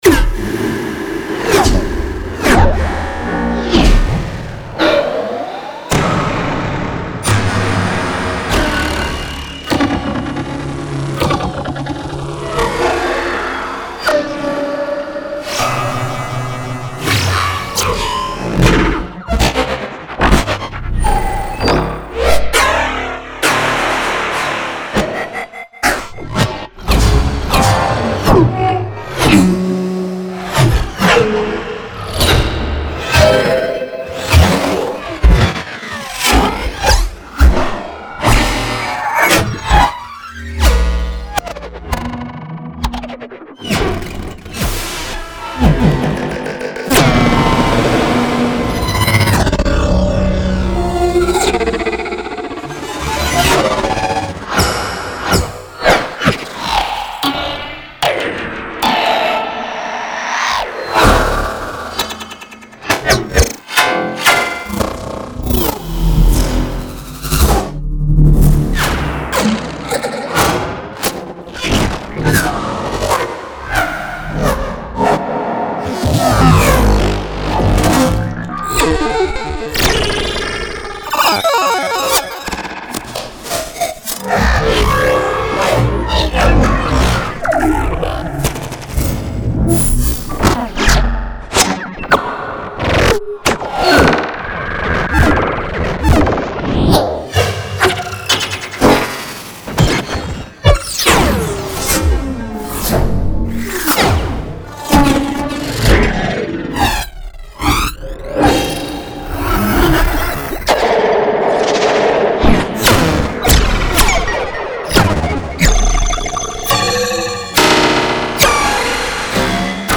【电影/游戏/武器FX音效采样包合集】Glitchmachines Deflect Bundle Vol.1-4
偏转功能1,179种金属撞击声效果，具有高科技的未来主义美学。